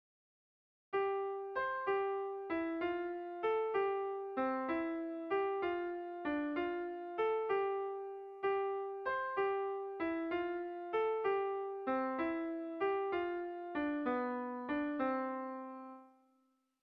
Dantzakoa
A1A2